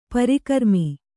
♪ parikarmi